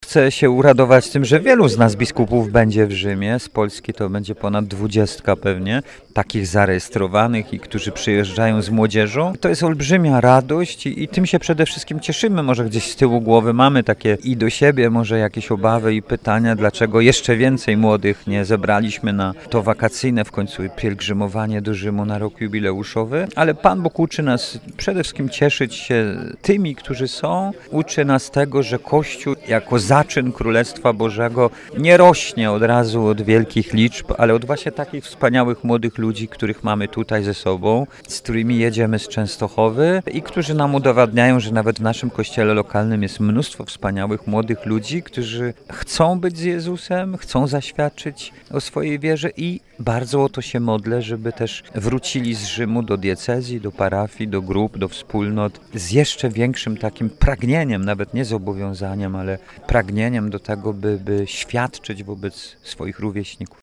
Bp Andrzej Przybylski nie krył radości z powodu obecności kapłanów, biskupów, ale przede wszystkim młodzieży, która licznie przybywa do Rzymu: